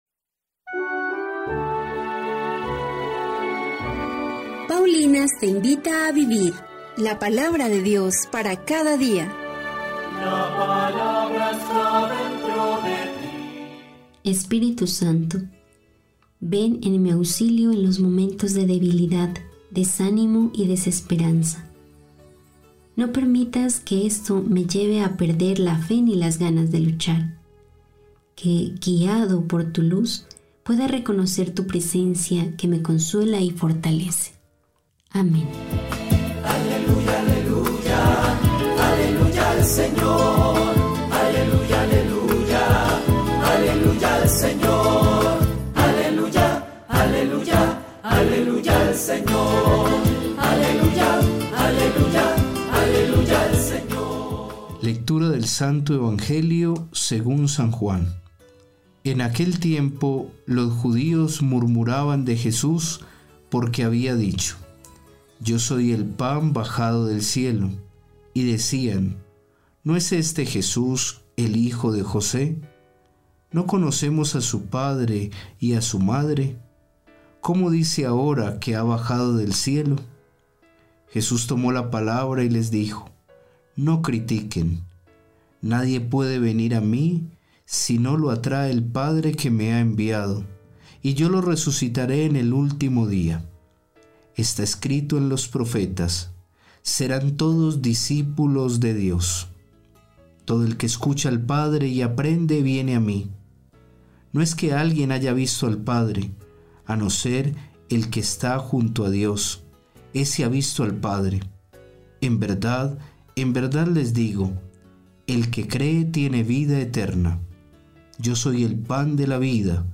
Lectura del libro del Deuteronomio 4, 32-40